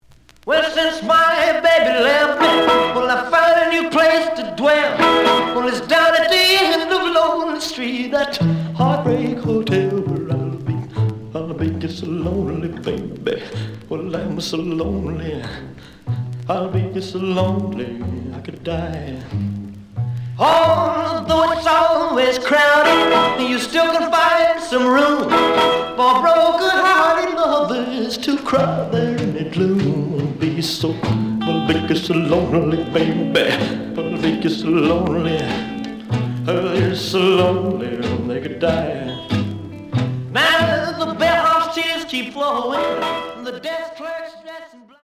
The audio sample is recorded from the actual item.
●Genre: Rhythm And Blues / Rock 'n' Roll
Slight affect sound.